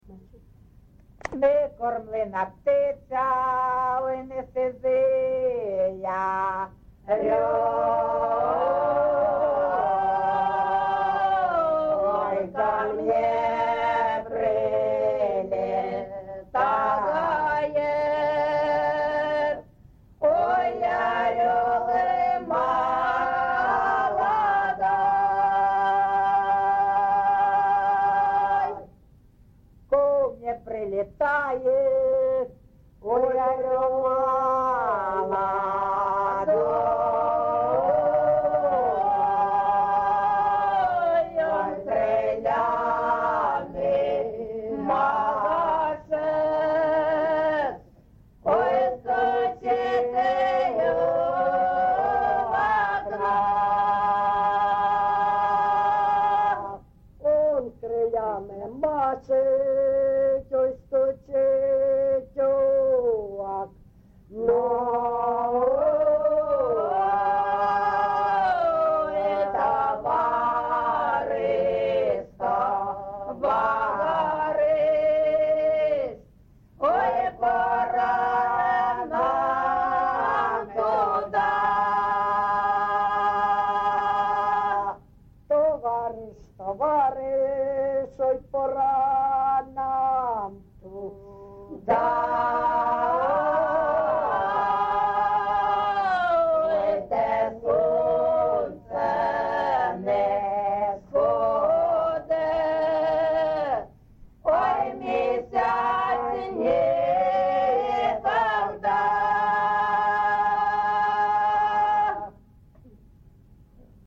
ЖанрПісні з особистого та родинного життя
МотивЖурба, туга
Місце записус. Іскра (Андріївка-Клевцове), Великоновосілківський (Волноваський) район, Донецька обл., Україна, Слобожанщина